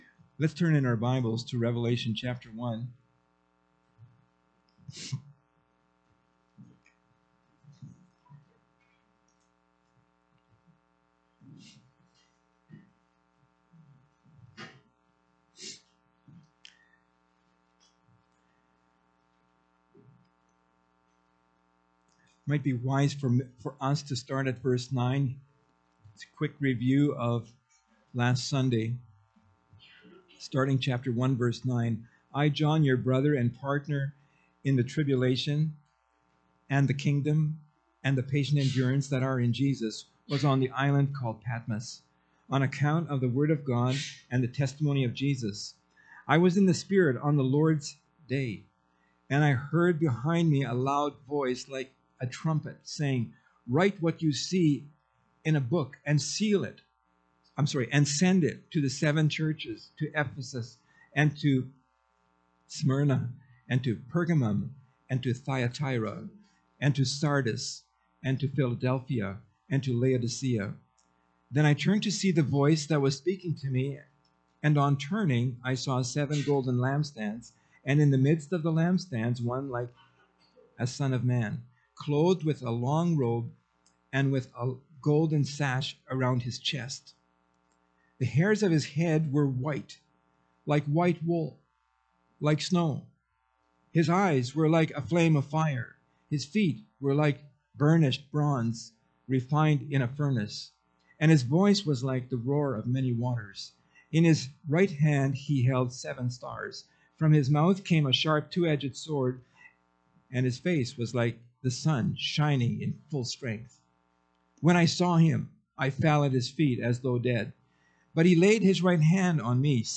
Category: Pulpit Sermons Key Passage: Revelation 1